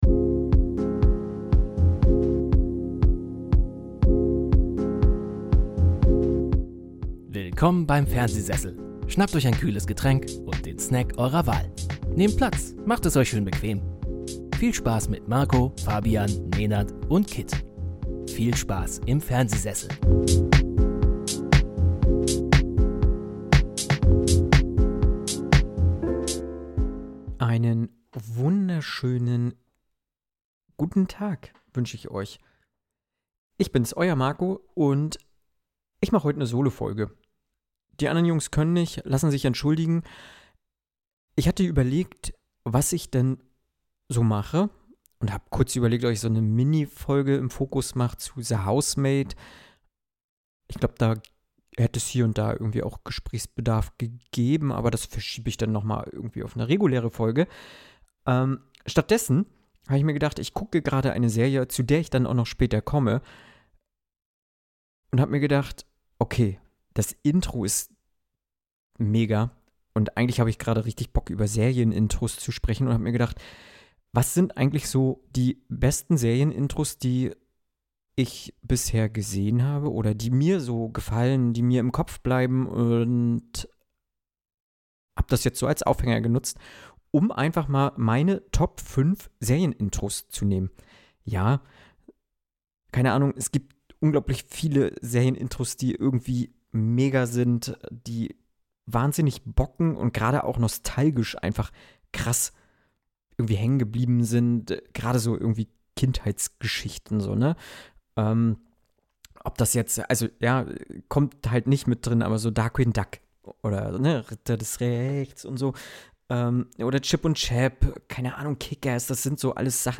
Beschreibung vor 2 Monaten Skip Intro? Nicht mit mir... In dieser Solo-Folge wird’s musikalisch, episch und manchmal herrlich wild: Ich spreche über die Kunst des Serien-Intros – diese 10 bis 90 Sekunden, die entscheiden, ob wir mitten in einer Welt landen… oder doch direkt auf „Überspringen“ drücken.